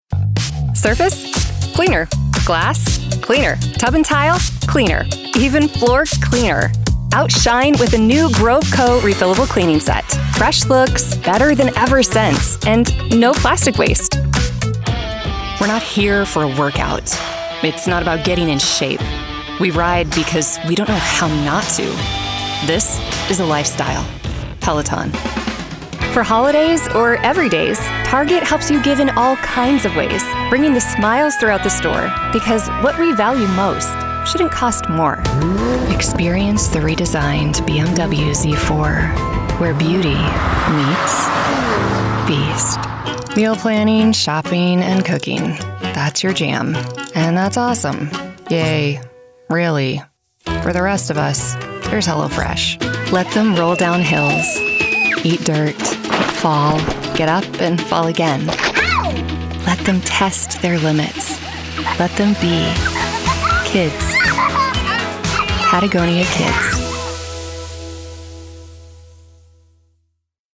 Commercial